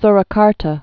(srə-kärtə)